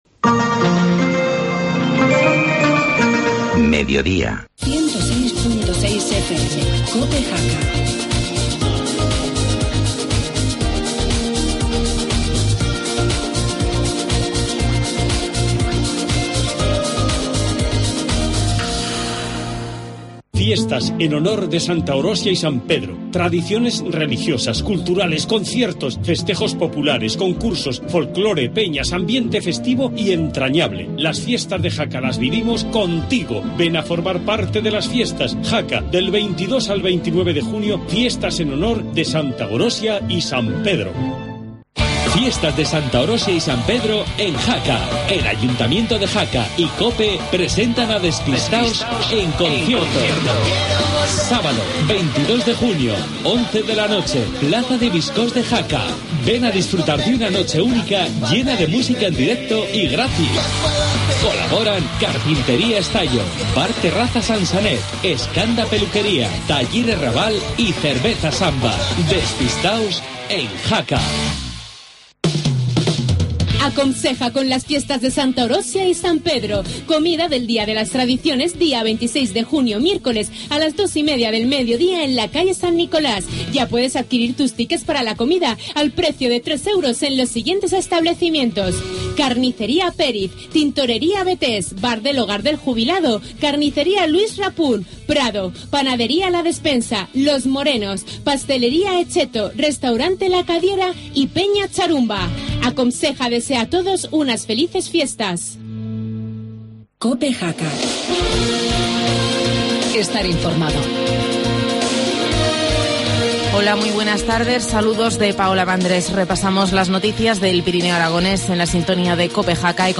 Informativo mediodía, martes 18 de junio